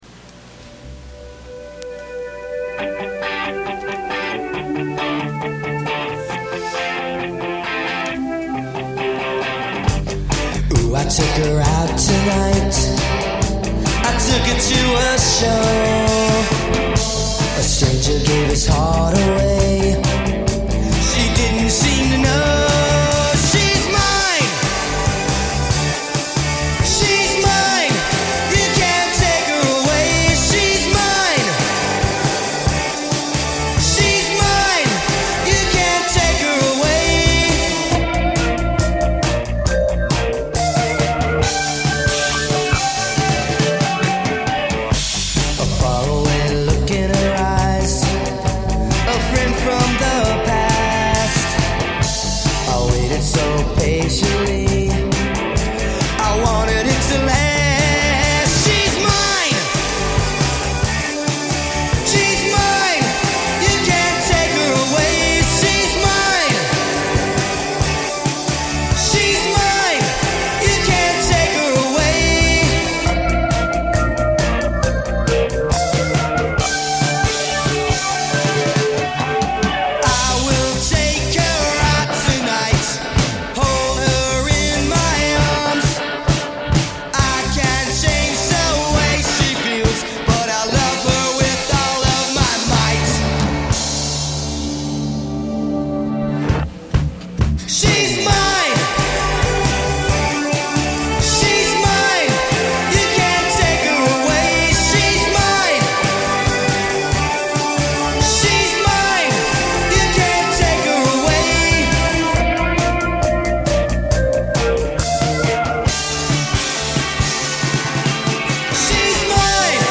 recorded in his basement